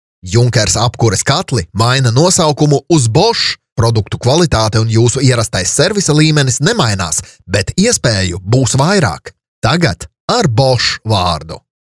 Professional Latvian Voice Over Artist | Native Latvian Voice Talent
Voice Over Artistes- LATVIAN